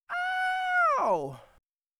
Треск в записи, help
Прислали на сведение вокал с явно слышимыми искажениями и треском. Вырезал один кусочег для образца, но там все дороги такие. Прям явно это заметно на громких местах вокала, но и на тихих оно тоже есть.